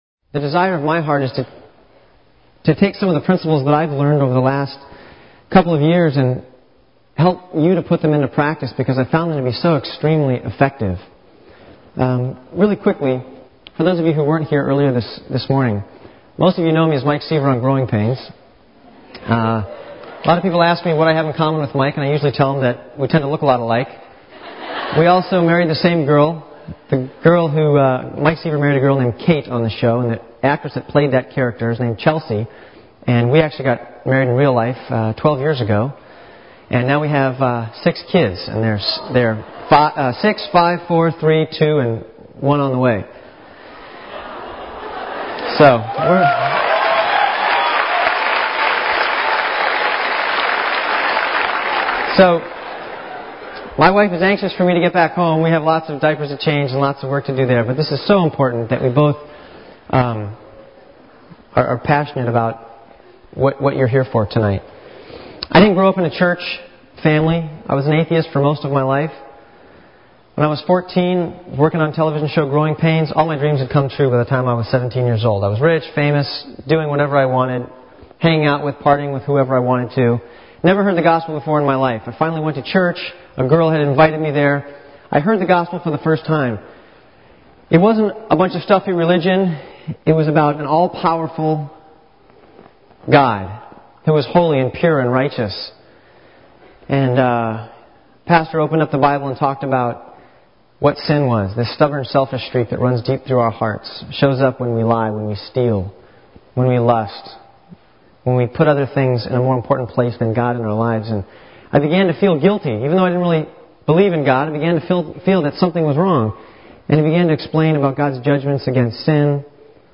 In this sermon, the preacher emphasizes the importance of sharing the gospel with others.